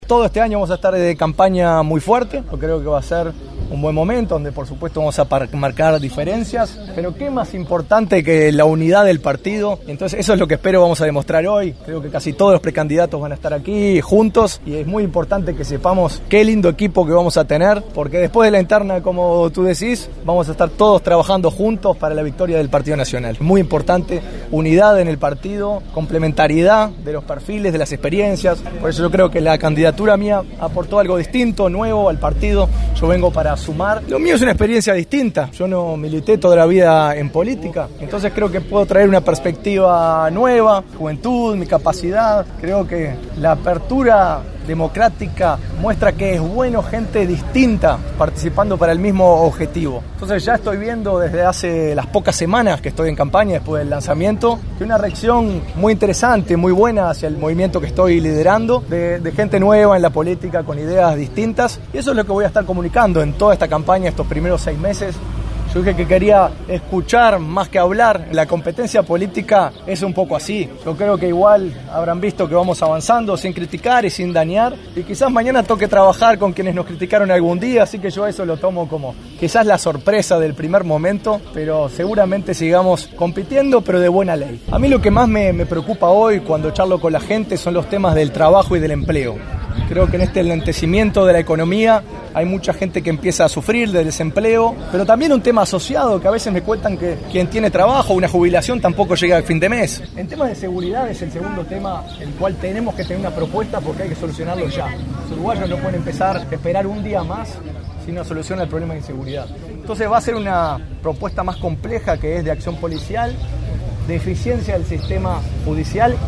El empresario y precandidato Juan Sartori, se pronunció en el marco de las celebraciones del 154 aniversario de la Defensa de la Heroica en el departamento de Paysandú.